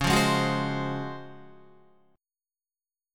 C#M11 Chord
Listen to C#M11 strummed